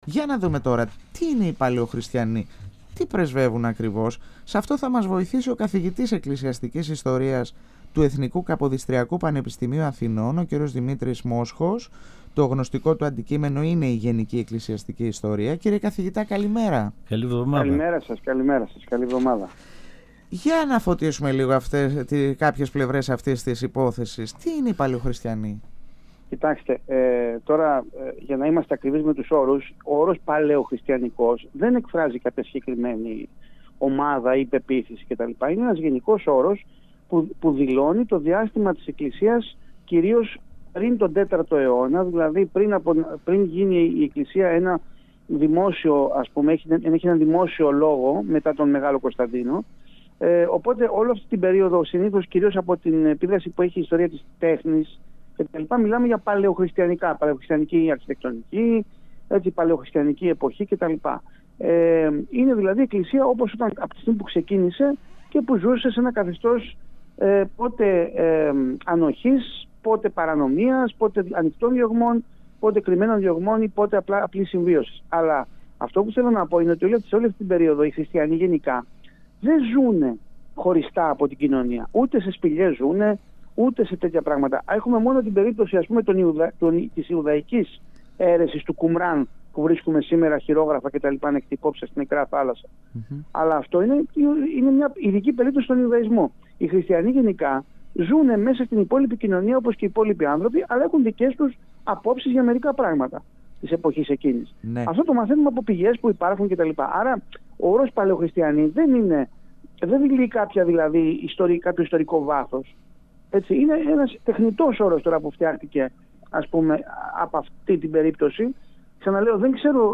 Οι ασκητές στο χριστιανισμό δεν είχαν όπλα». 102FM Εδω και Τωρα Συνεντεύξεις ΕΡΤ3